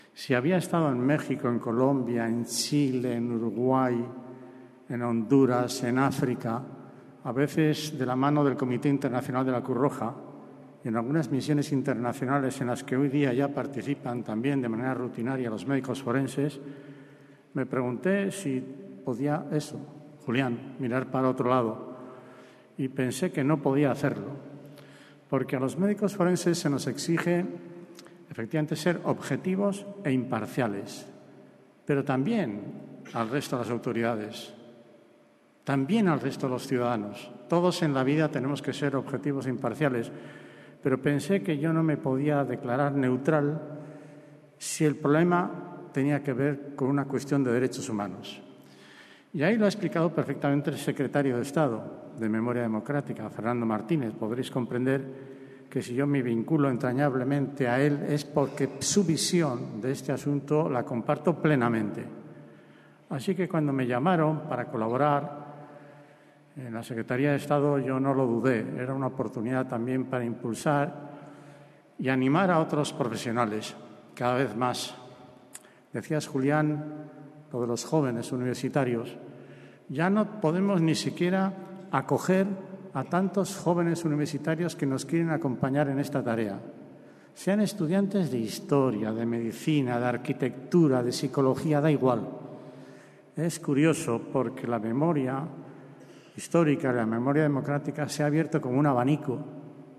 CORTES DE VOZ
Francisco Etxeberria_Antropólogo y Médico Forense